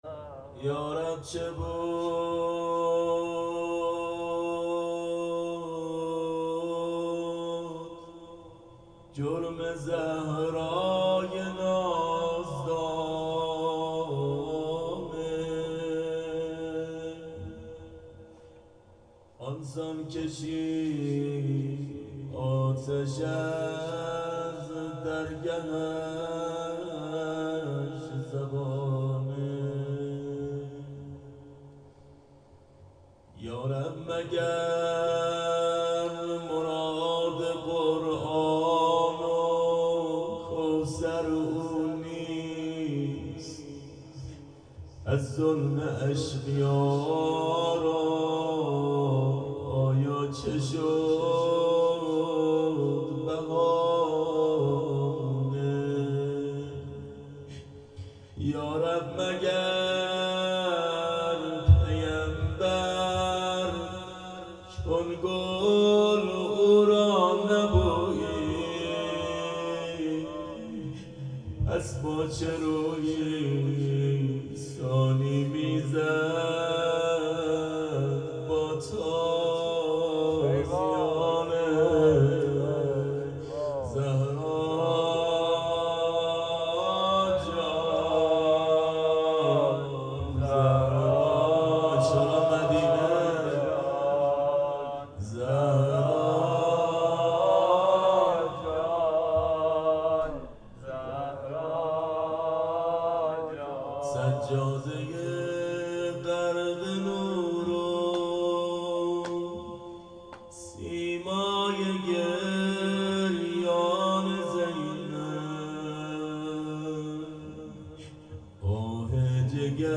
روضه حضرت زهرا سلام الله علیها.mp3